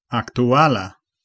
Ääntäminen
Synonyymit moderne Ääntäminen France: IPA: [aktyɛl] Tuntematon aksentti: IPA: /ak.tɥ.ɛl/ Haettu sana löytyi näillä lähdekielillä: ranska Käännös Ääninäyte Adjektiivit 1. aktuala 2. laŭmoda 3. nuna Suku: m .